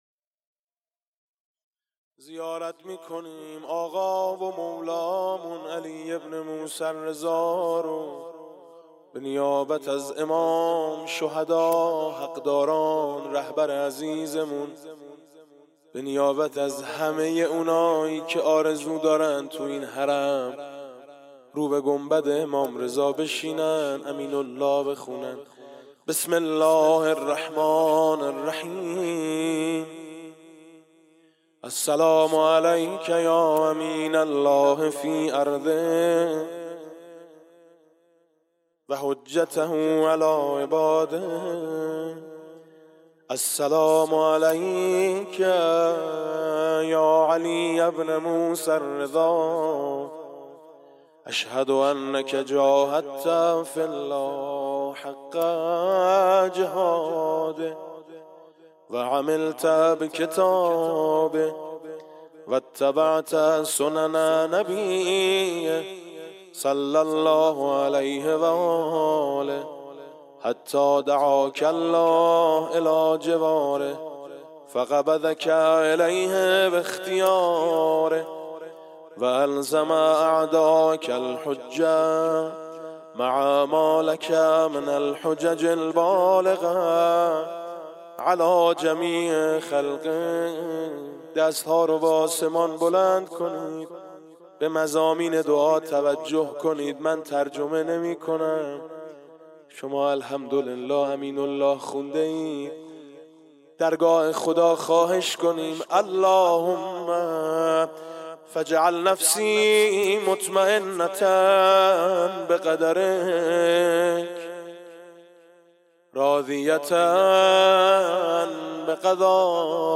ولادت امام باقر (ع) 96 - حرم امام رضا (ع) - زیارت امین الله